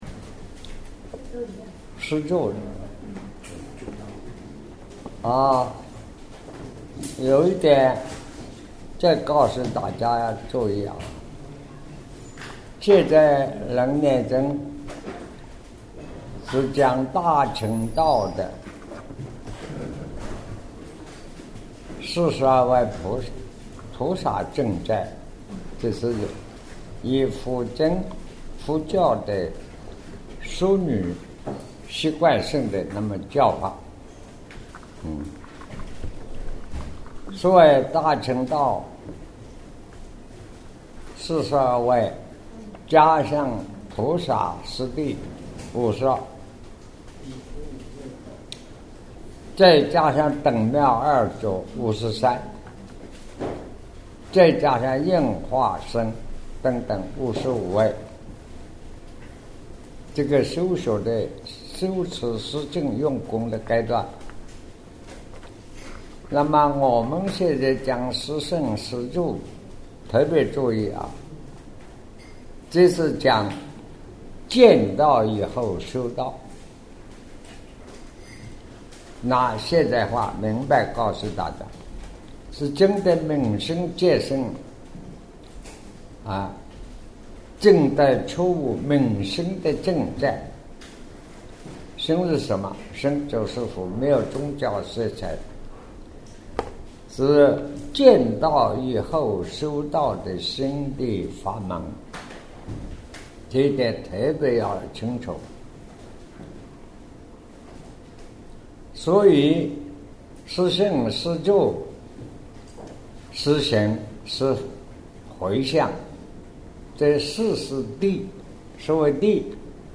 南怀瑾先生2009年讲楞严经113 卷八 五十五位修行圣位 十住位